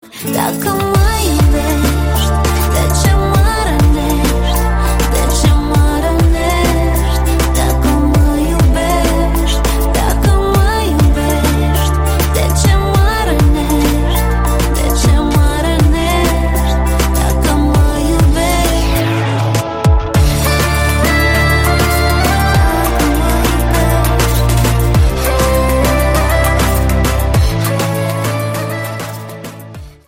• Качество: 128, Stereo
румынские